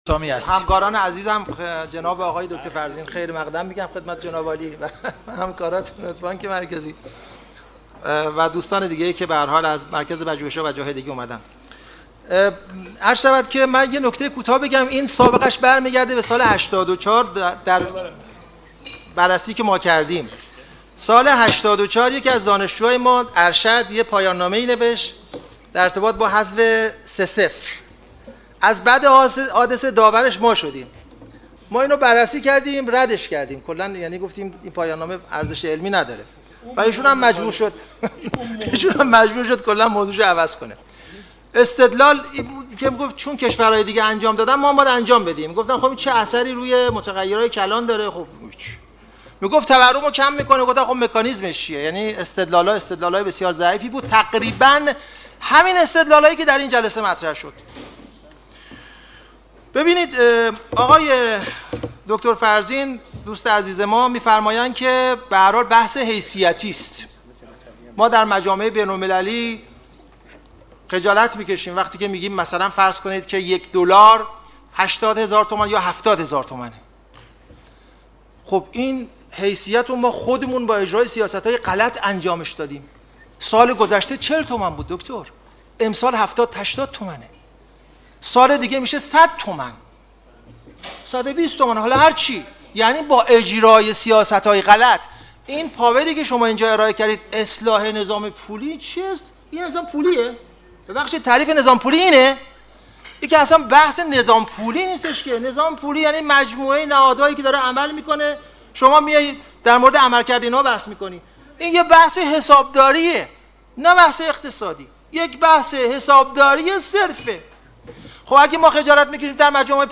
مخالف طرح حذف چهار صفر از پول ملی در گفت‌و‌گو با آنا